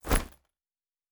Fantasy Interface Sounds
Bag 08.wav